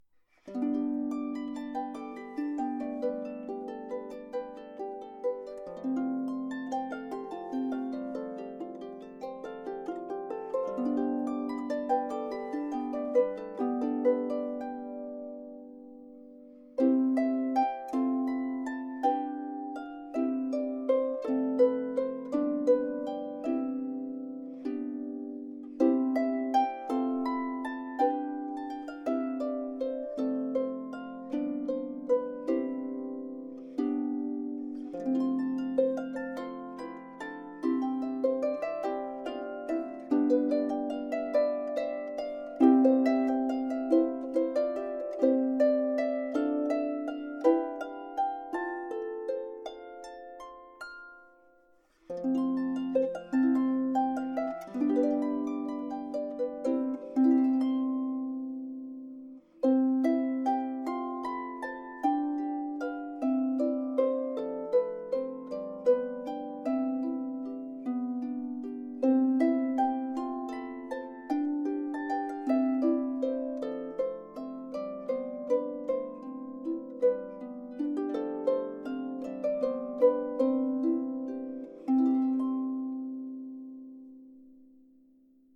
Harfe "Ivy"
klein & klangstark
Ivy - Freie Improvisation 2.mp3